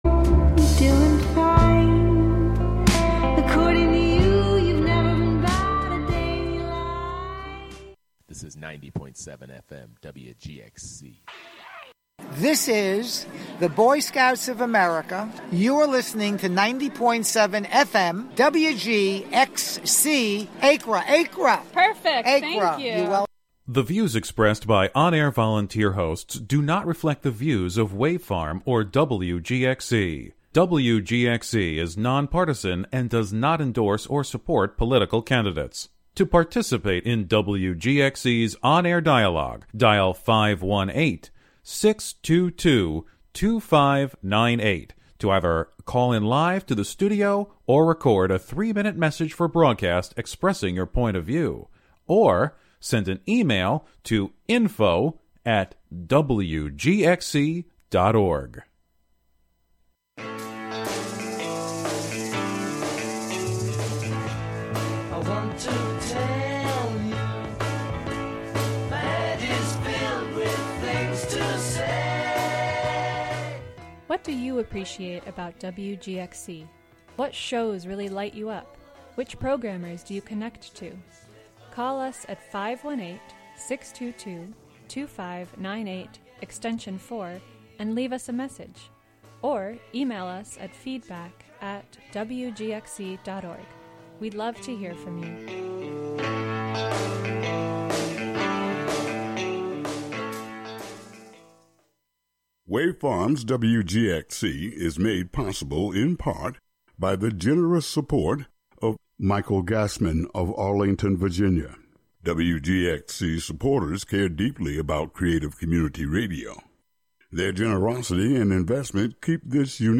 short stories and fables from around the world that are available in print will be the only topic here. i won’t offer commentary or biographies on-air. just the fictions read for the listener as best as i can read them.